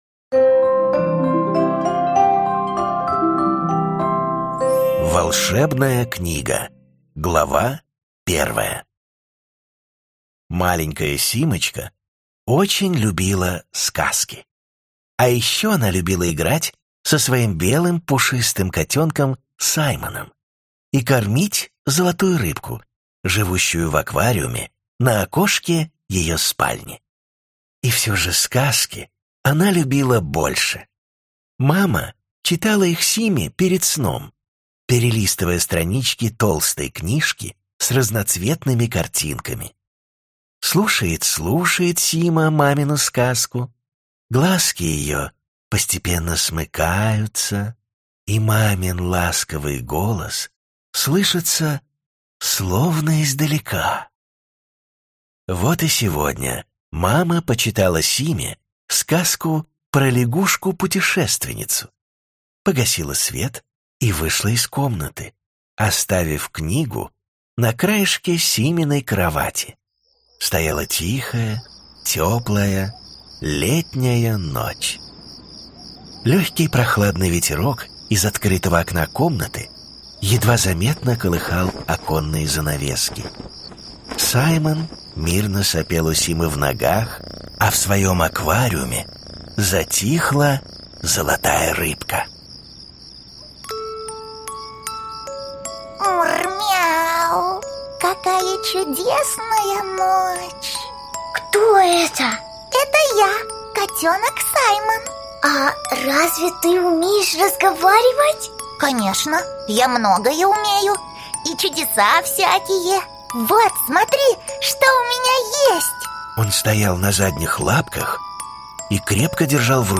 Аудиокнига Волшебная книга.